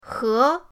he2.mp3